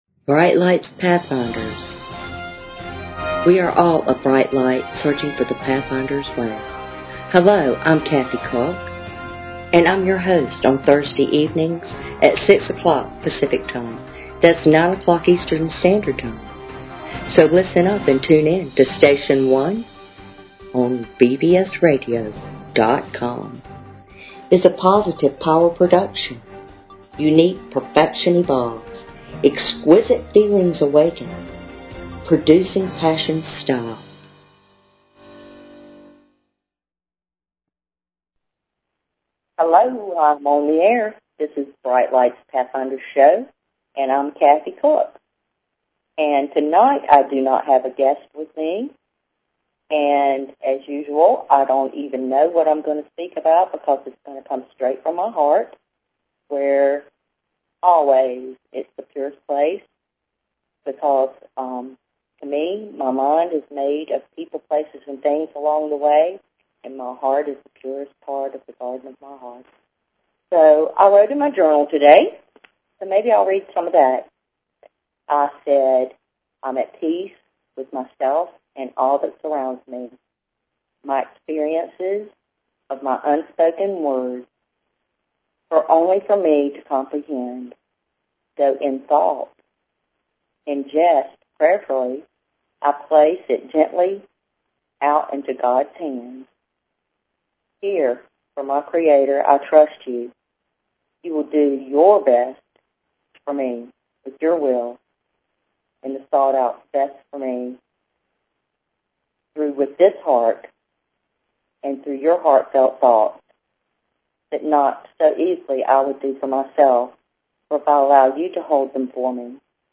Talk Show Episode, Audio Podcast, Brightlights_Pathfinders and Courtesy of BBS Radio on , show guests , about , categorized as